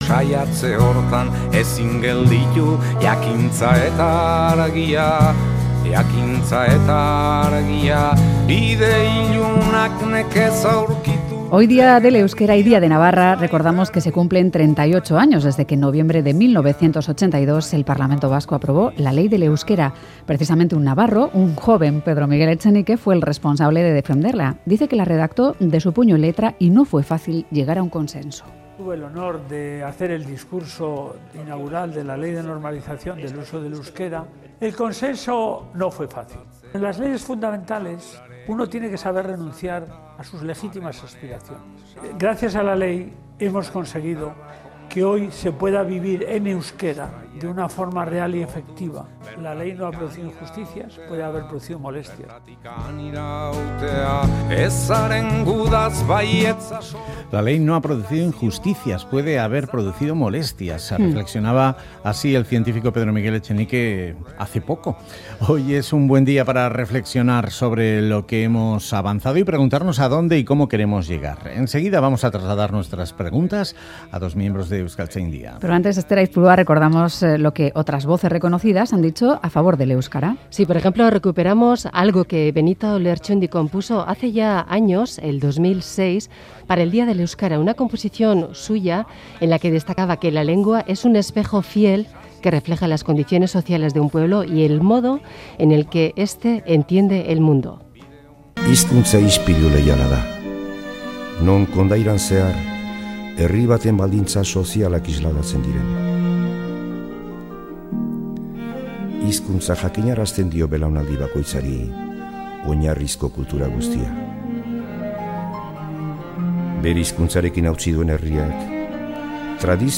Audio: En el Día Internacional del Euskera hablamos con 2 personas que trabajan para que el euskara siga vivo y se adecúe a los nuevos tiempos y usos.